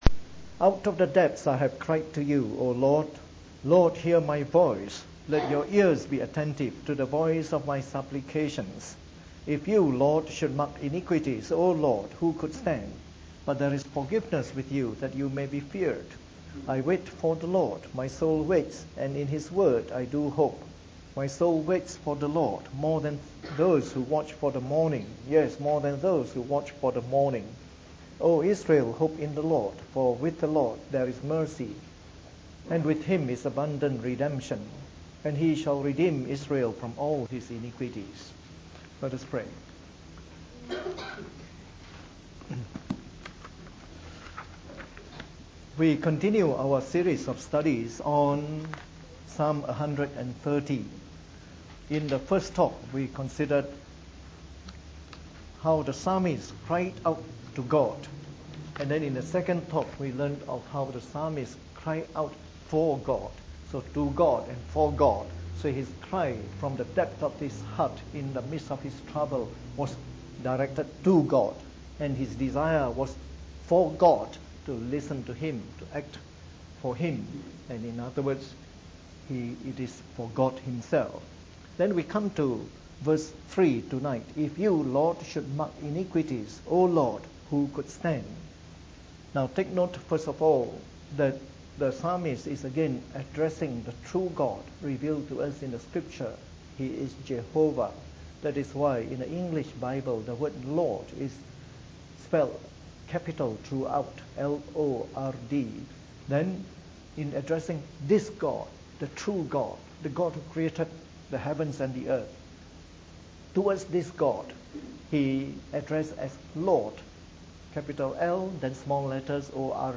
Preached on the 7th of August 2013 during the Bible Study, from our series of talks on Psalm 130.